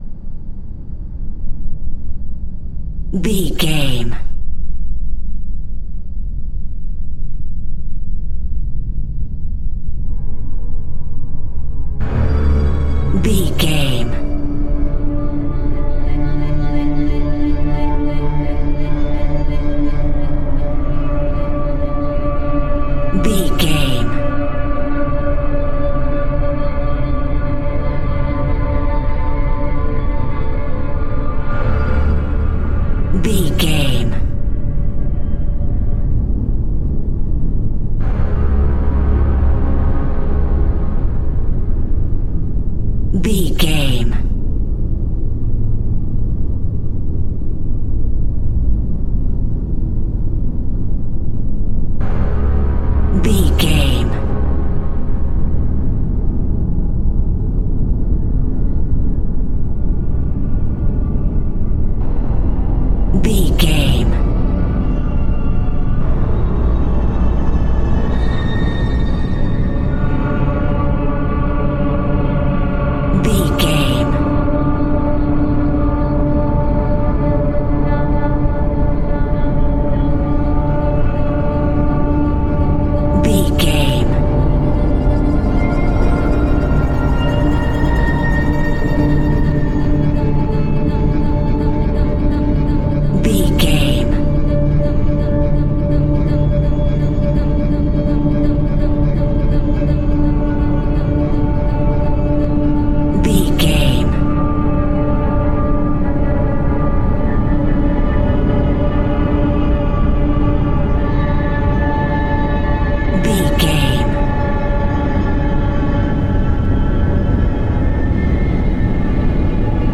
TV Horror.
Aeolian/Minor
Slow
ominous
haunting
eerie
strings
synthesiser
ambience
pads